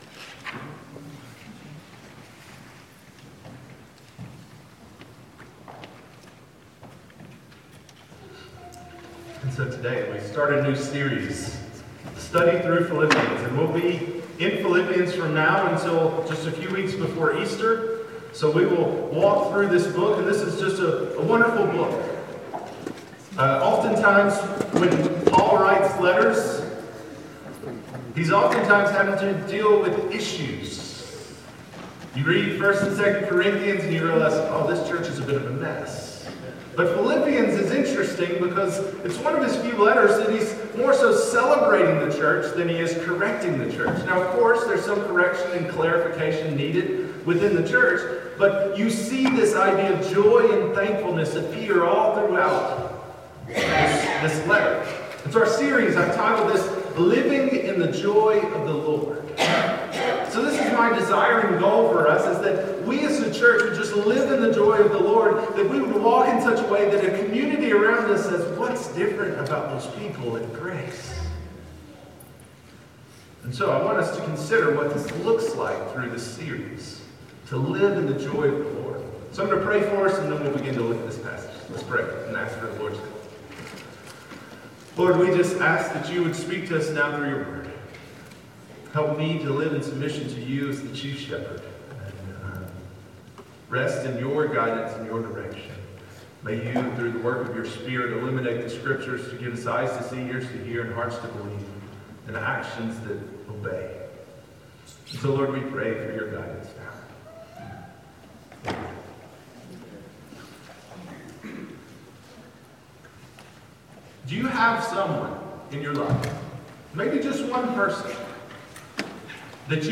Passage: Philippians 1:1-11 Sermon